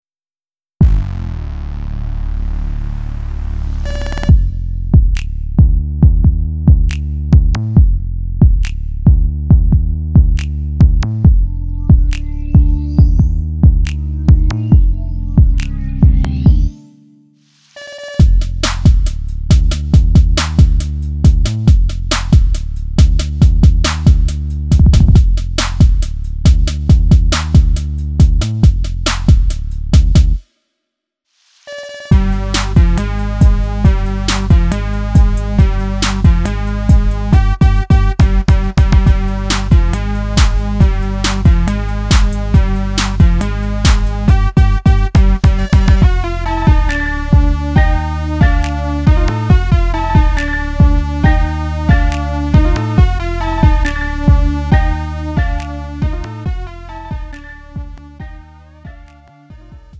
음정 -1키 2:40
장르 가요 구분